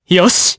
Arch-Vox_Happy4_jp.wav